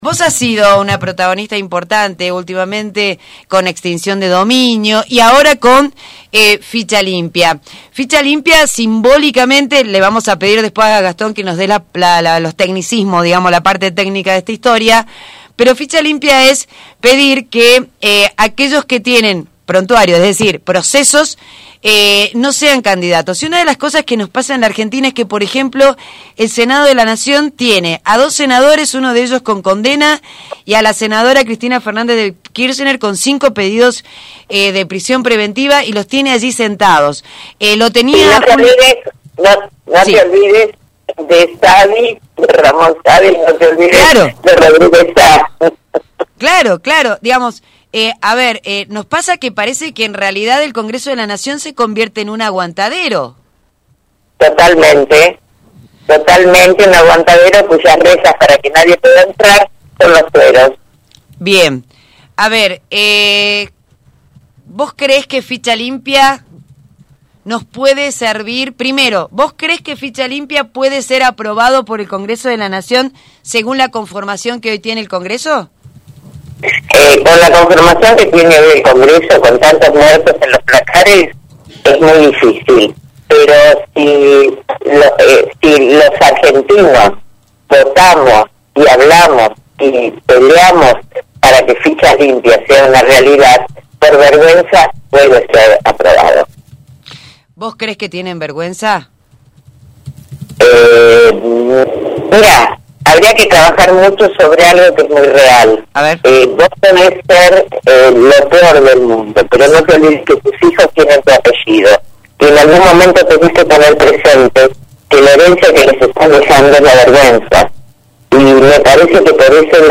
entrevistó a los periodistas